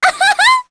Shamilla-Vox_Happy1_kr.wav